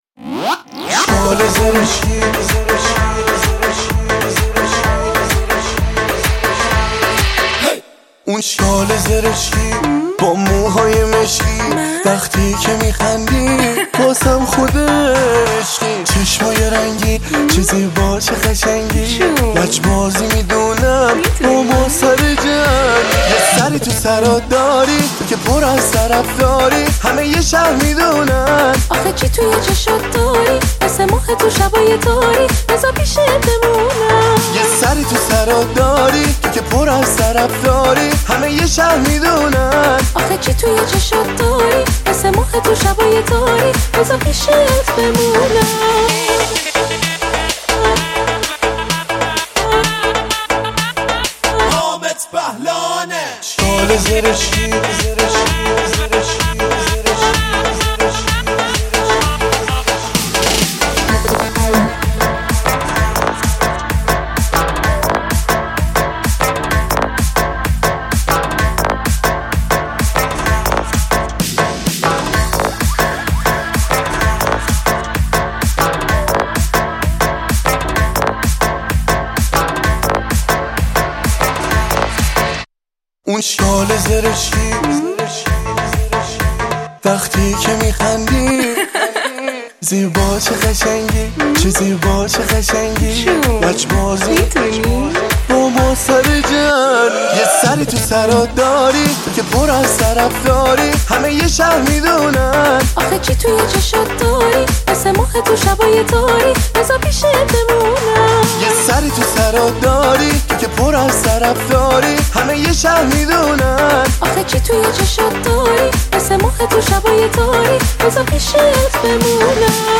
شاد و بسیار جذاب